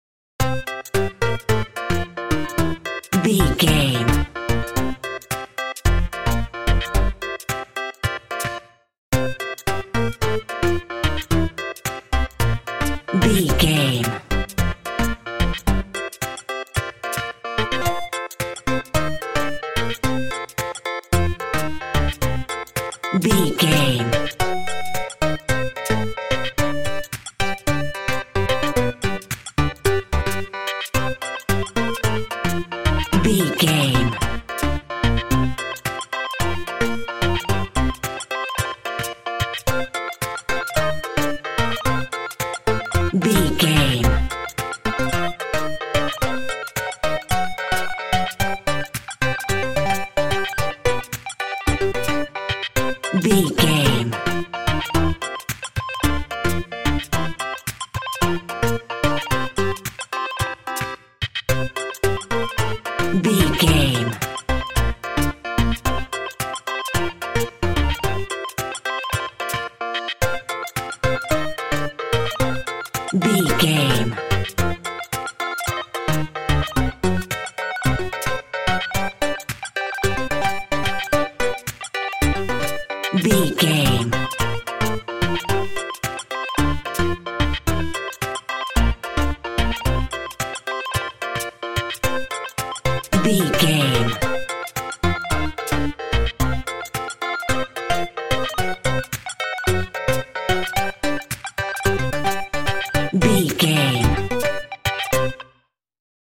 Mixolydian
8bit
bouncy
medium tempo
synthesiser
drum machine